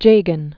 (jāgən), Cheddi Berret 1918-1997.